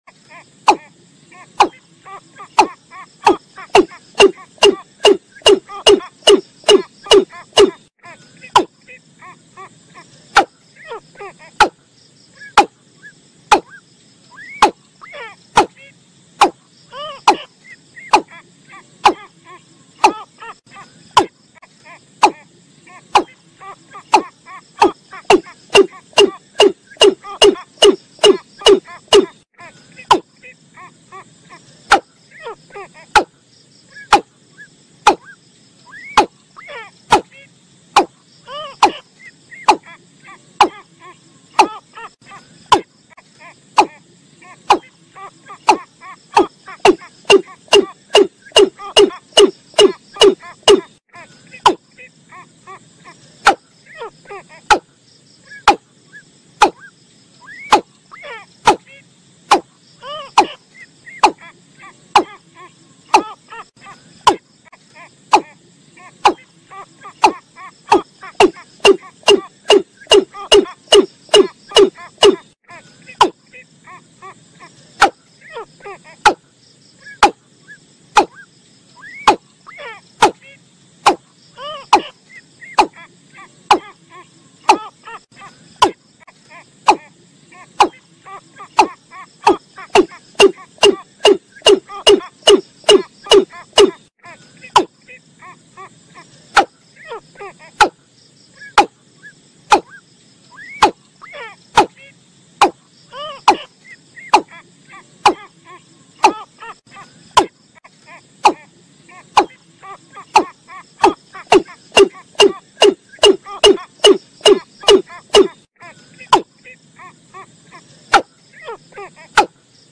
เสียงนกอีลุ้มตัวผู้ผสมตัวเมีย
Download, ดาวน์โหลดเสียงนกอีลุ้มที่เป็นการผสมผสานระหว่างตัวผู้และตัวเมียเป็นไฟล์เสียง mp3 สำหรับการต่อนะคะ เสียงนกอีลุ้มที่ผสมรวมกันระหว่างตัวเมียและตัวผู้เป็นเสียงของนกแท้จริง คุณสามารถดาวน์โหลดไฟล์เสียงนี้เพื่อเพลิดเพลินกับความชัดระดับ 100% และตัดเสียงรบกวนออกไปอย่างแน่นอนค่ะ